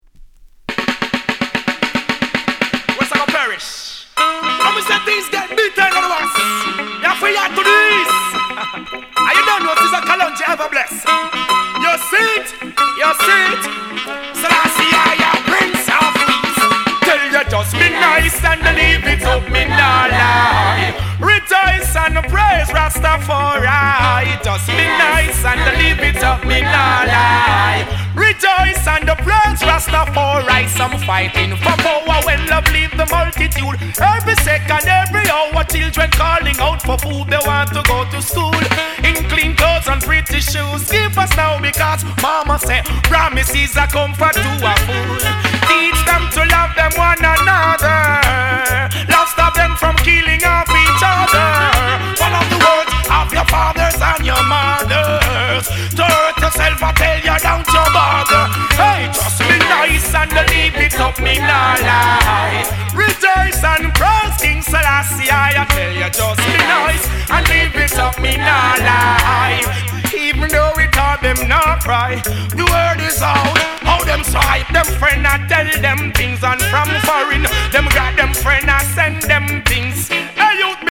Genre: Reggae/ Dancehall